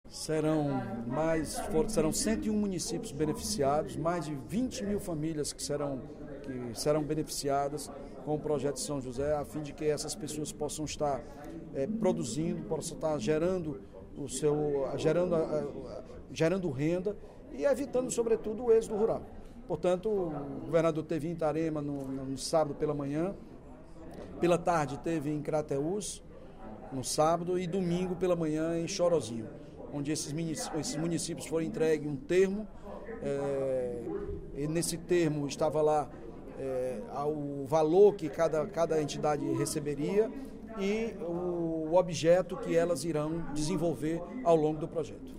O líder do Governo na Assembleia Legislativa, deputado Evandro Leitão (PDT), destacou, no primeiro expediente da sessão plenária desta terça-feira (21/03), os benefícios e investimentos para o desenvolvimento da agricultura do Estado anunciados pelo governador Camilo Santana, no último fim de semana. O parlamentar explicou que as medidas contemplam projetos produtivos, sistemas de abastecimento de água, reúso de água, assessoria técnica e mapeamento de solo, alcançando  mais de 20 mil famílias de 101 municípios cearenses .